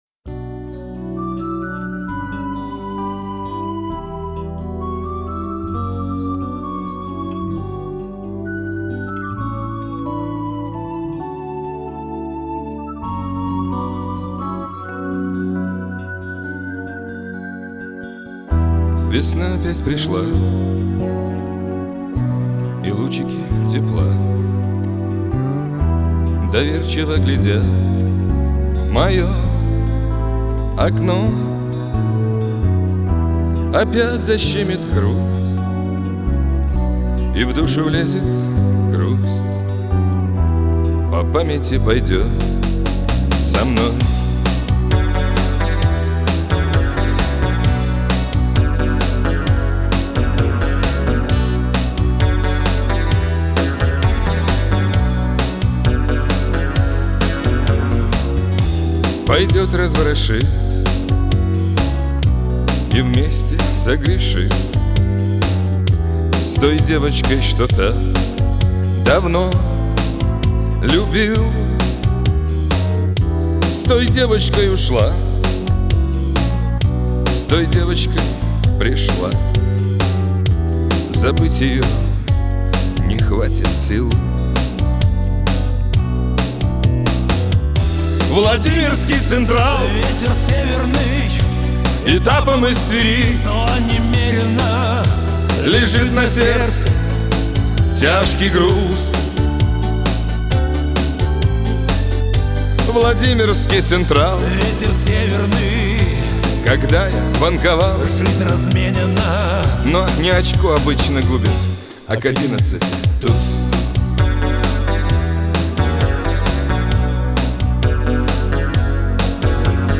Шансон песни